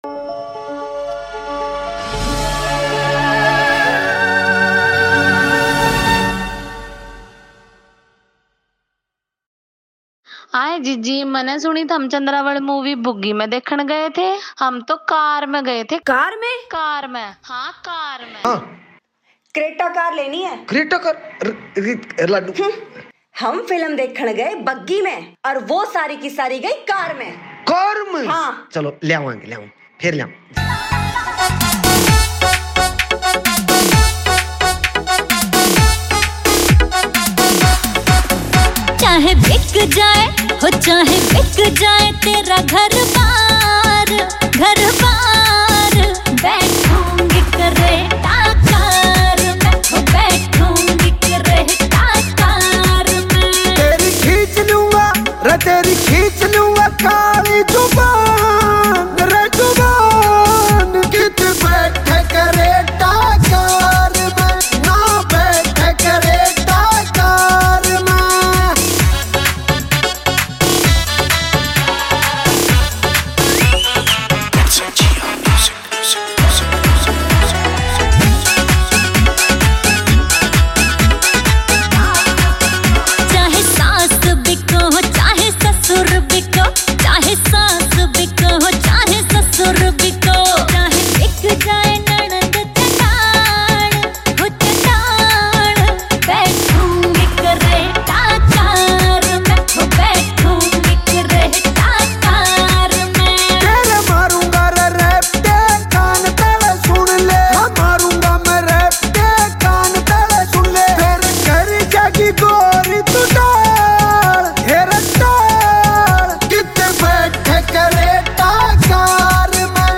Location : Khawab Studio, Fathehabad
"Haryanvi Folk Song"